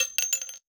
weapon_ammo_drop_06.wav